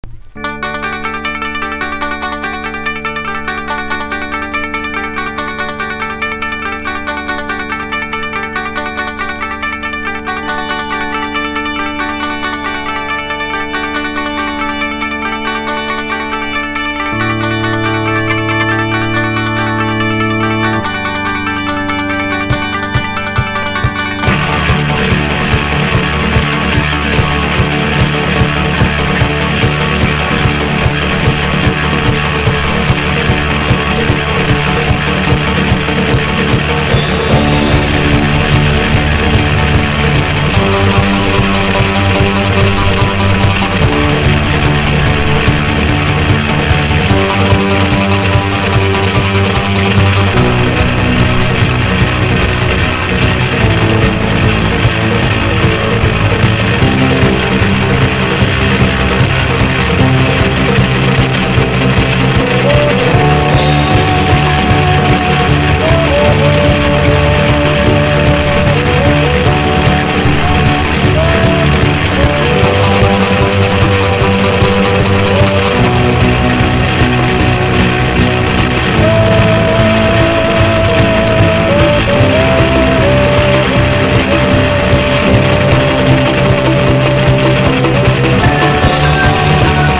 1. 00S ROCK >
NEO ACOUSTIC / GUITAR POP (90-20’s)